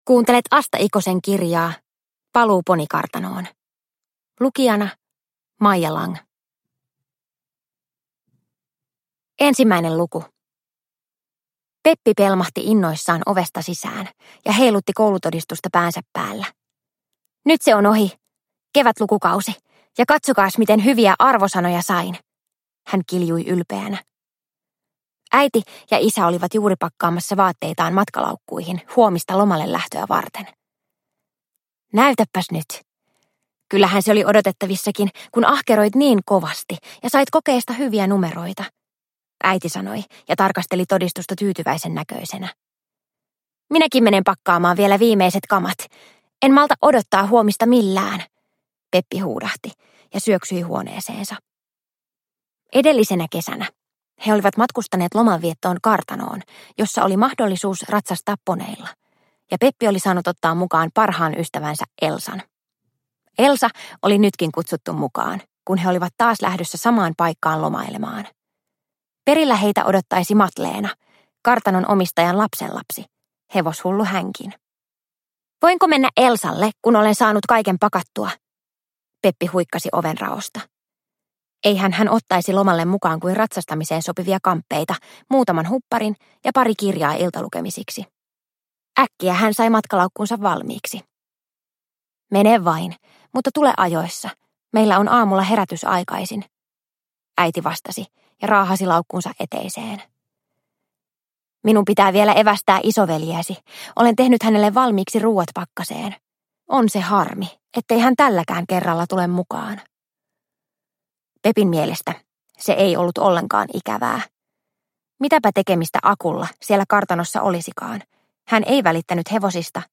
Paluu ponikartanoon – Ljudbok – Laddas ner